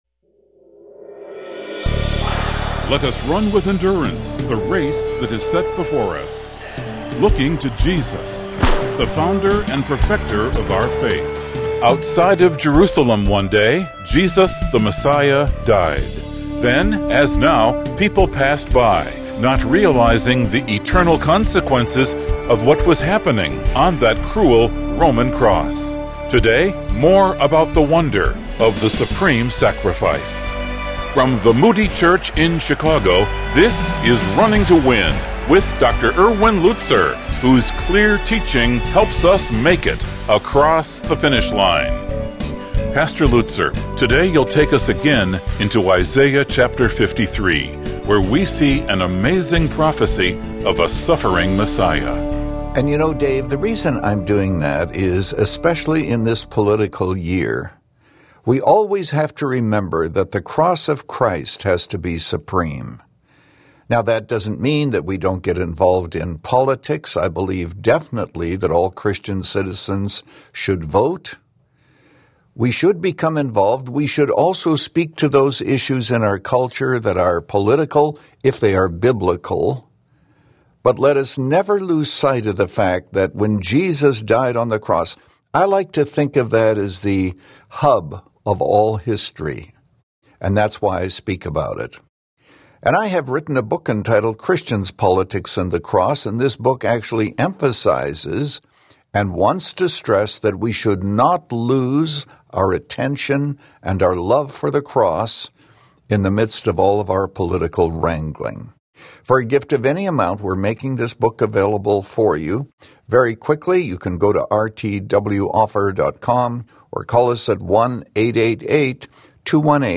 Skeptics question God's love. But we must point them to the cross where the Son of God suffered for our sake and died. In this message from Isaiah 53